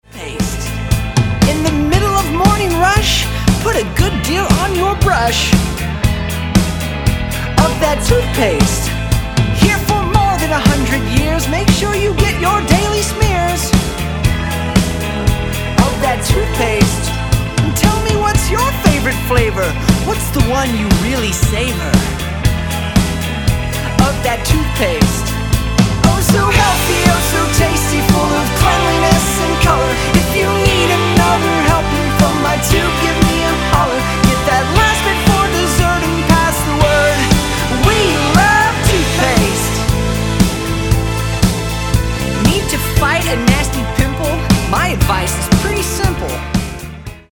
sung by US singer